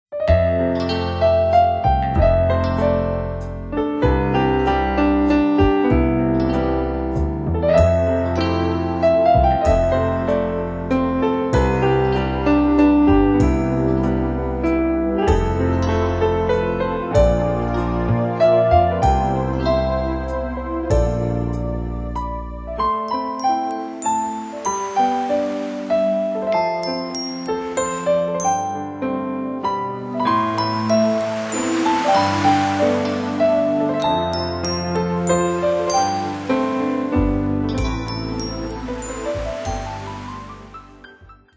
〜 フュージョン、ポップス系中心のネットゲームのサウンドトラック盤。
ジャンルとしては、フュージョンを中心に、ポップス、クラシック風まで多彩です。